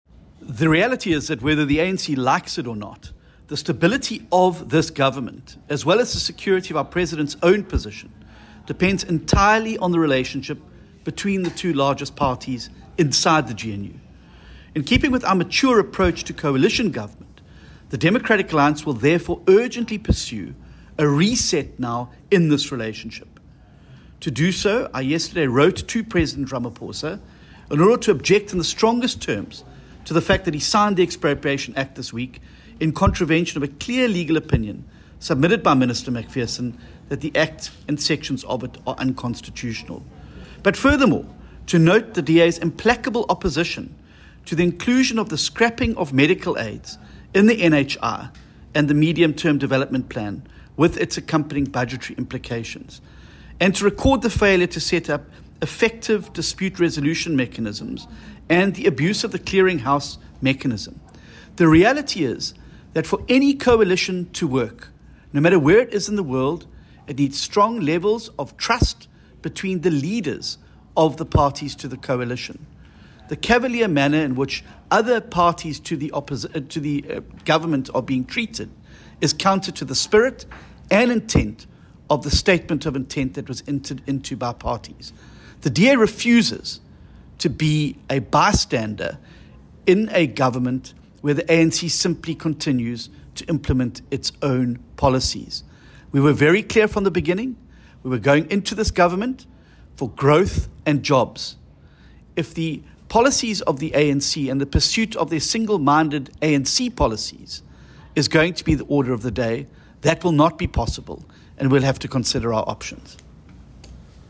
soundbite by John Steenhuisen MP.